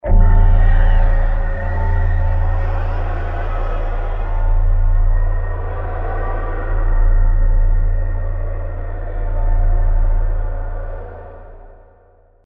Root > sounds > weapons > hero > enigma